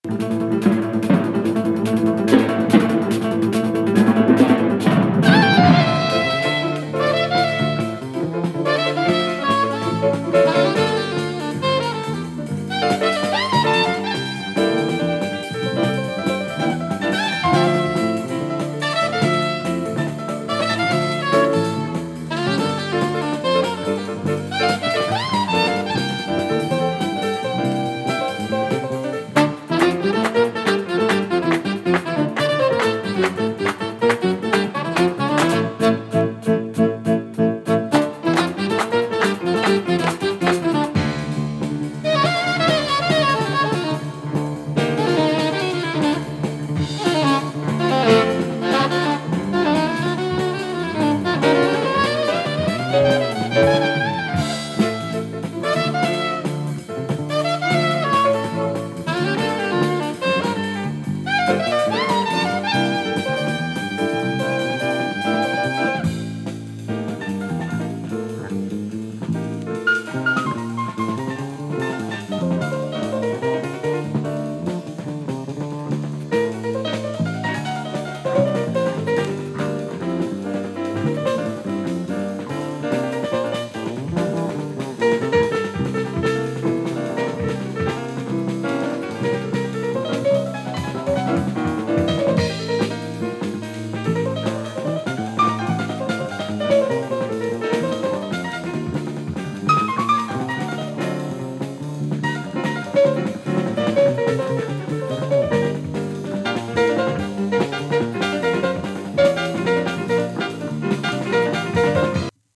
東欧ジャズ 疾走 コズミック フュージョン